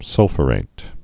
(sŭlfə-rāt, -fyə-)